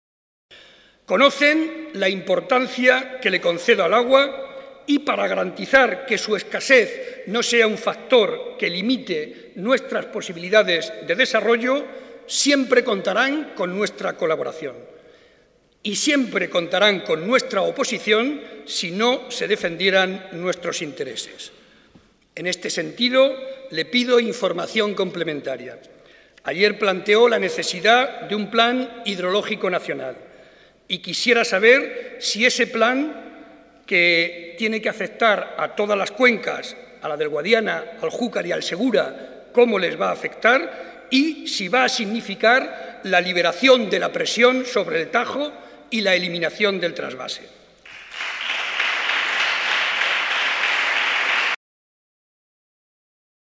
Cortes de audio de la rueda de prensa
audio_Barreda_Discurso_Debate_Investidura_210611_1